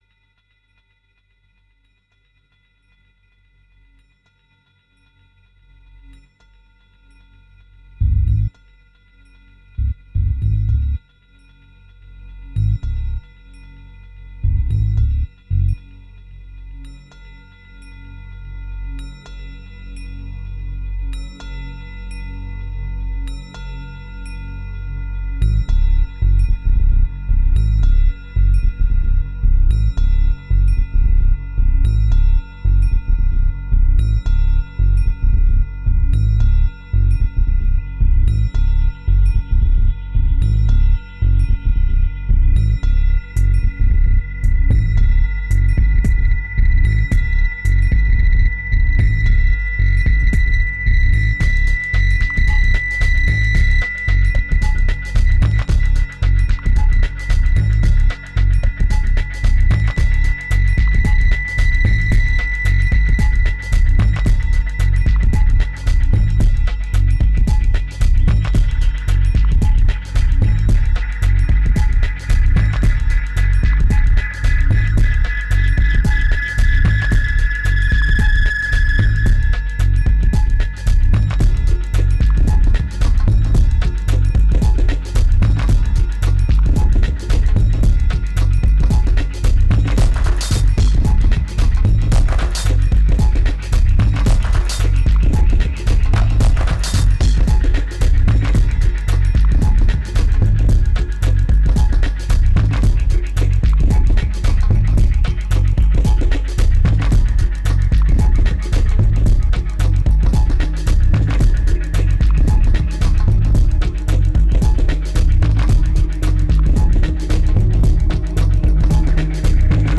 It's raw, it's dirty and it's lame, but are you up to it ?